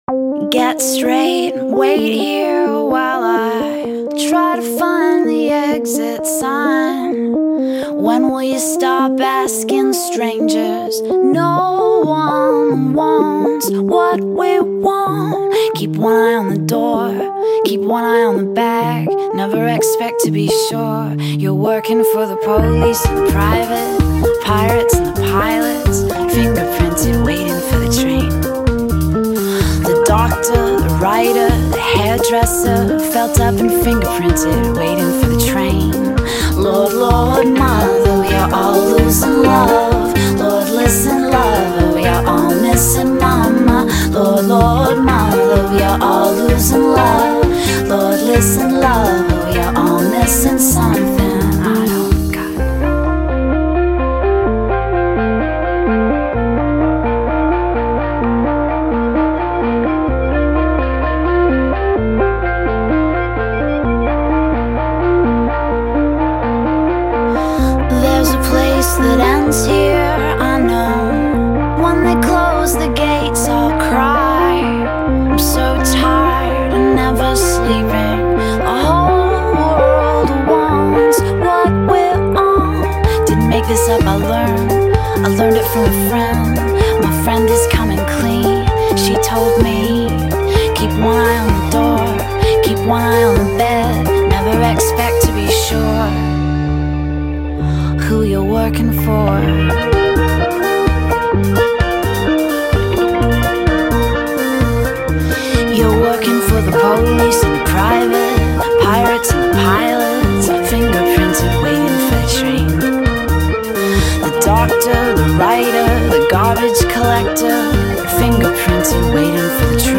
1. The synth. Just a hint of UK new-wave influence.
I love its strength and its raspiness.
Tags2000s 2005 Canada new wave Rock